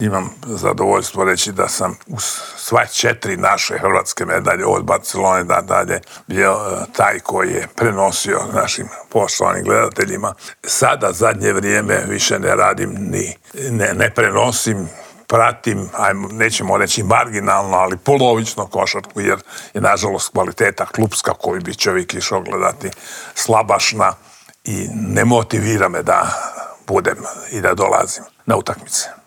Kako sve krenulo prema dolje, pokušali smo odgonetnuti u Intervjuu tjedna Media servisa u kojem je gostovao bivši TV komentator, legendarni Slavko Cvitković.